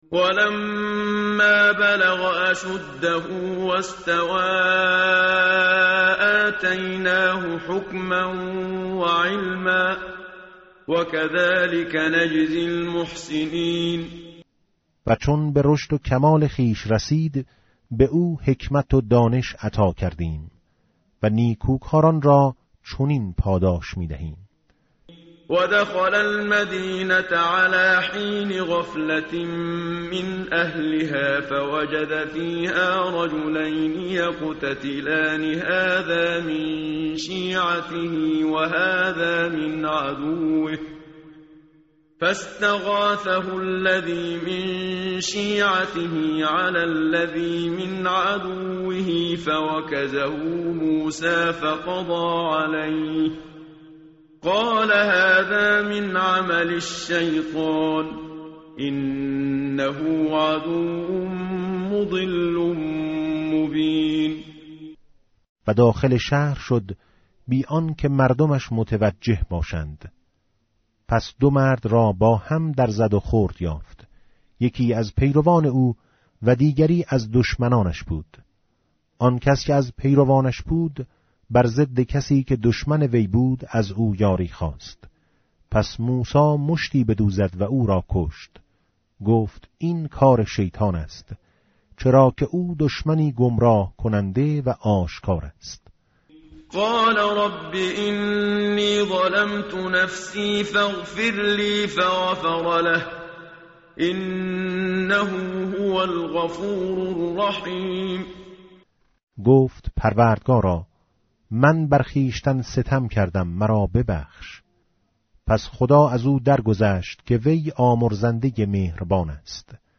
متن قرآن همراه باتلاوت قرآن و ترجمه
tartil_menshavi va tarjome_Page_387.mp3